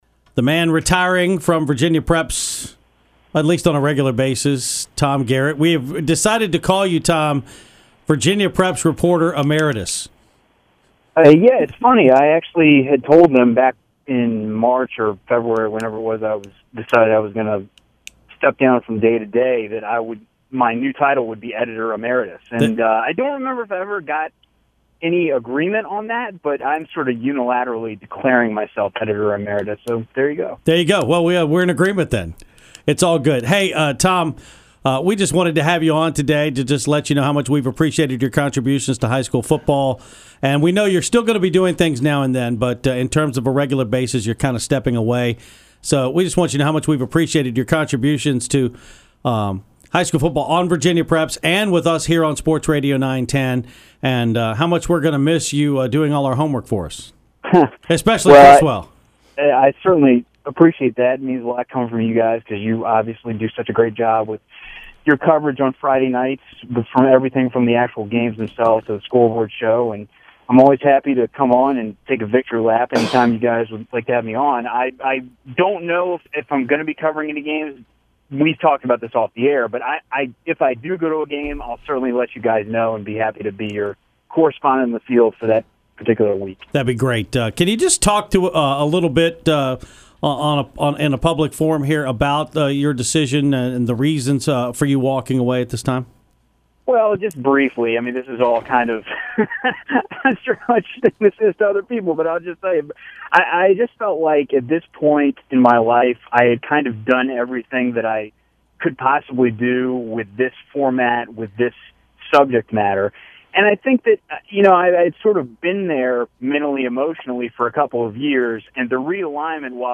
Here’s the interview: